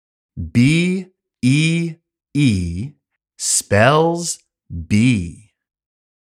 単語の読み方・発音